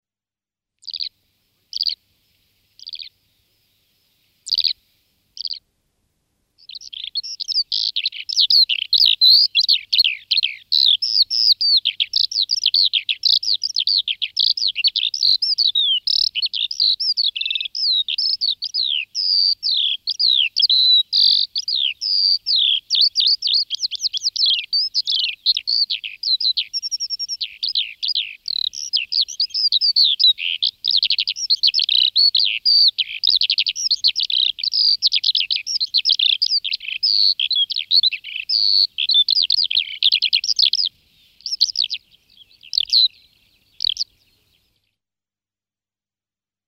Chant :
Alouette des champs
L' Alouette des champs grisolle, tire-lire, turlute. L'alouette des champs mâle a un chant complexe. Le chant est émis en vol. On peut entendre un sifflement « trli » ou un « dji » sonore, sur des tonalités variées. Les trilles et les trémolos sont souvent répétés avec des vitesses, des tonalités, des longueurs et des timbres différents.
Quand l'alouette des champs chante au sol, le chant est plus calme et plus court, avec des gazouillis et des pauses. La même séquence mélodieuse peut durer plus d'une heure.
23Skylark.mp3